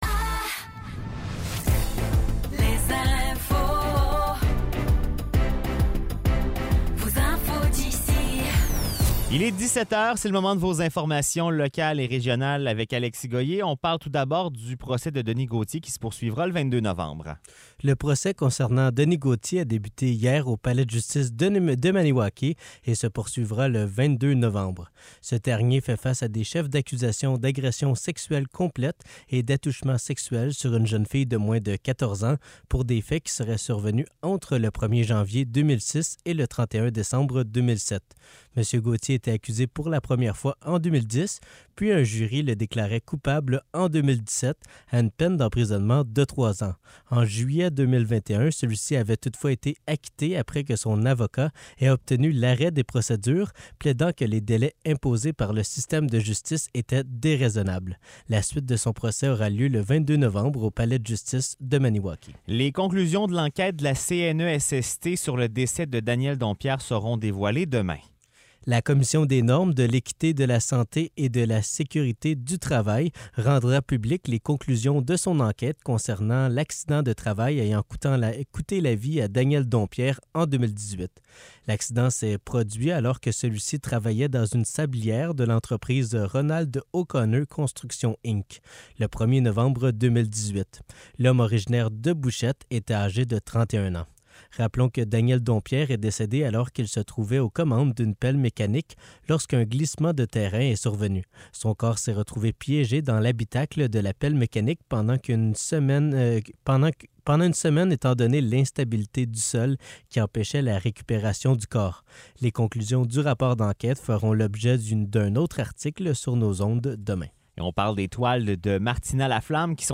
Nouvelles locales - 17 octobre 2023 - 17 h